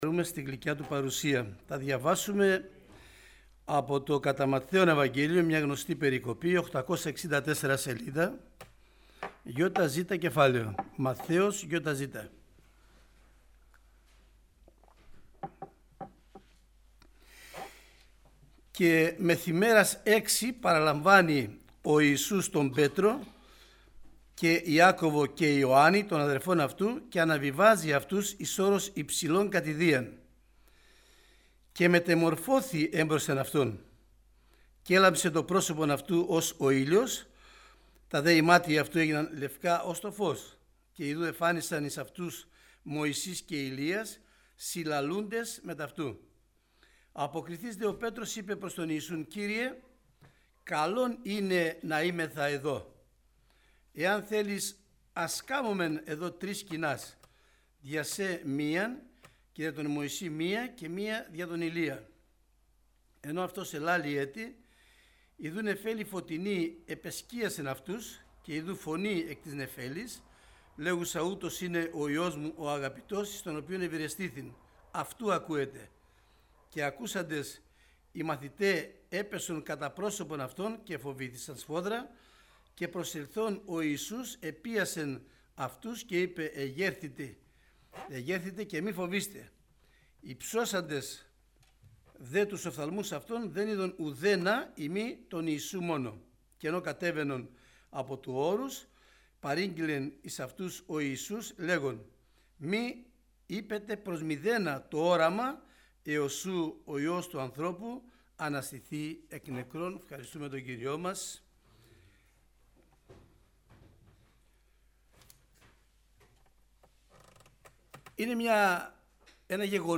Κηρυγμα Ευαγγελιου